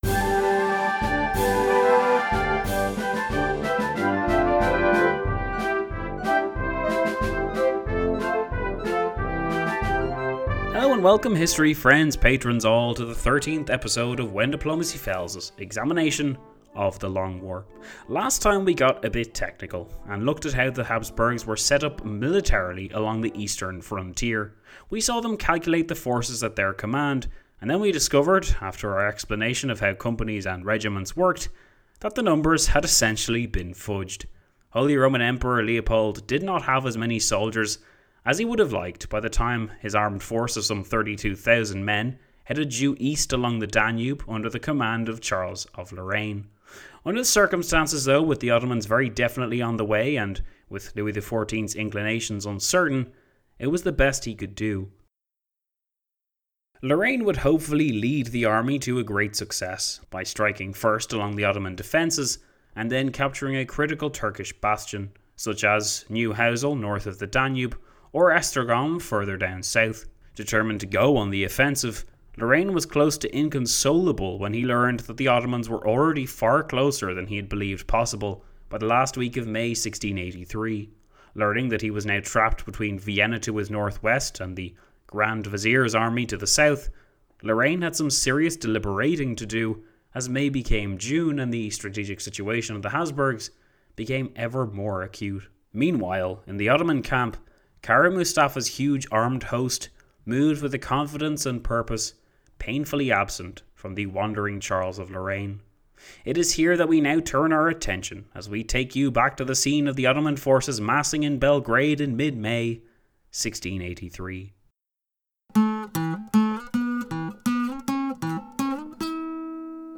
Whatever I call it, it's the River Raab not the River 'Raba'.